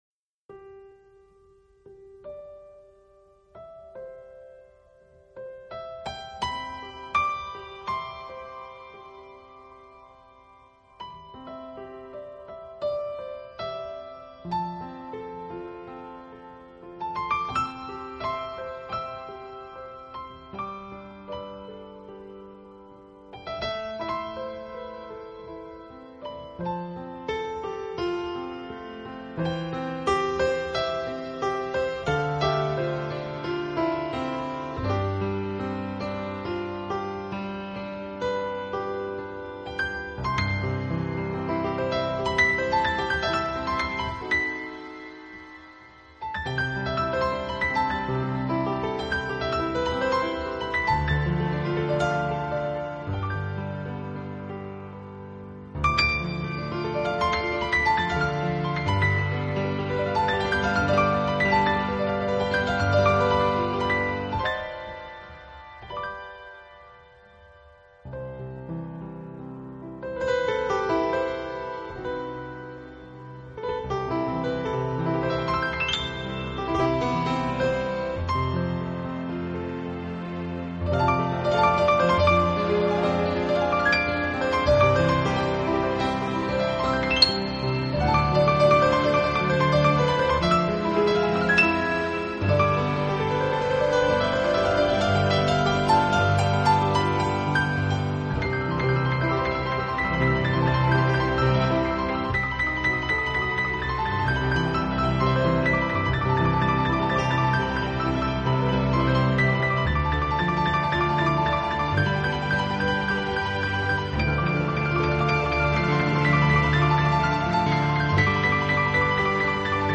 【纯美钢琴】
旋律赋予自然而感性的转折